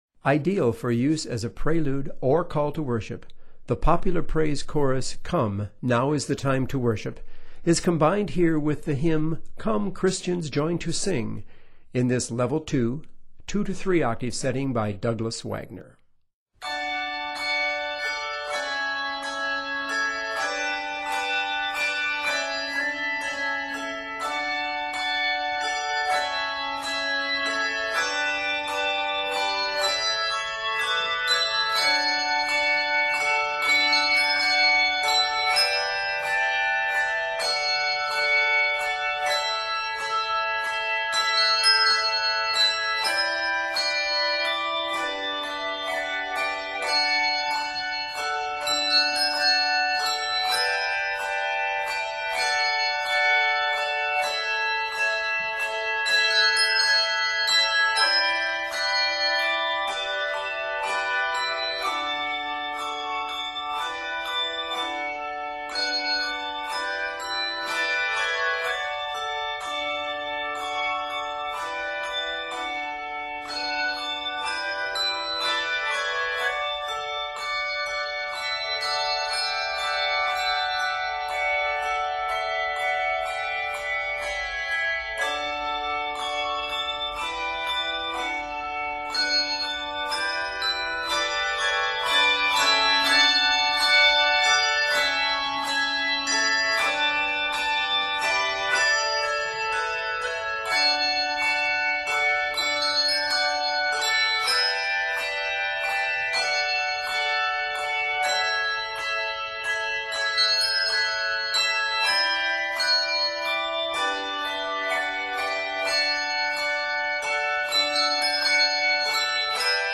for 2-3 octaves of handbells
set in D Major and is 68 measures